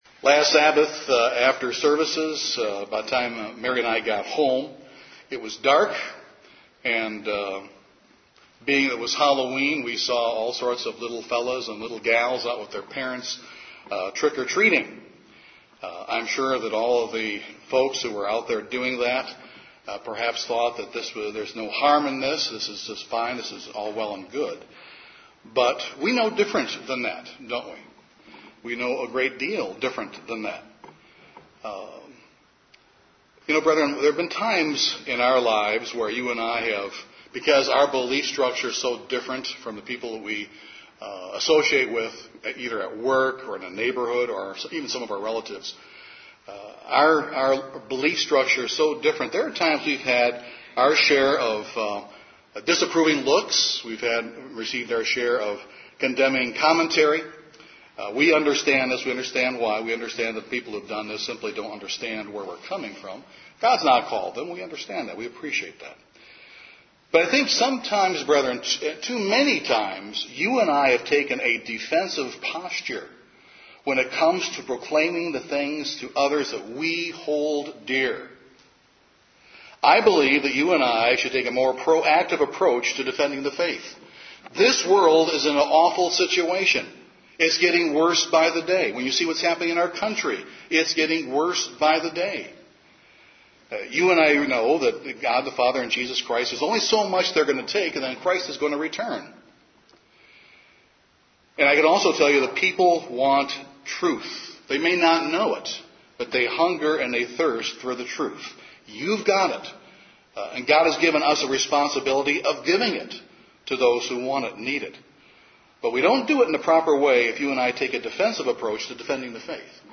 This sermon gives food for thought as to how we can proactively respond to our questioners with a positive, vigorous defense of our beliefs.